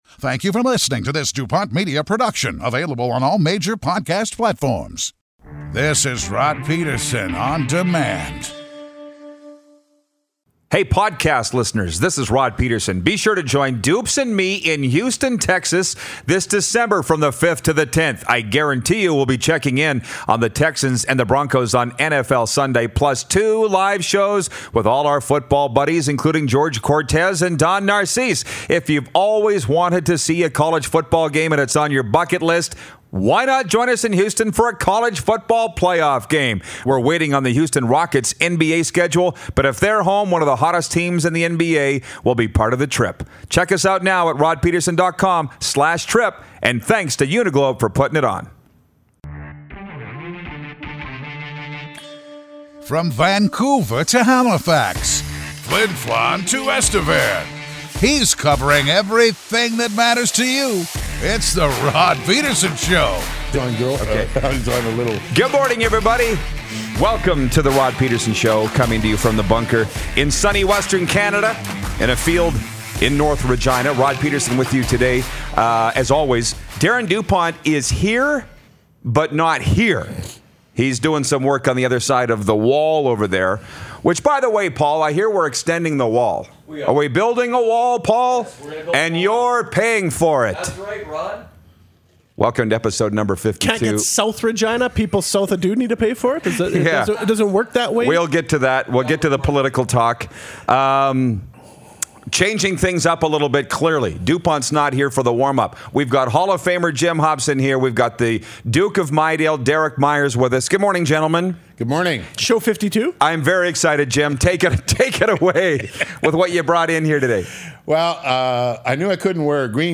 CFL on TSN Analyst Milt Stegall calls in!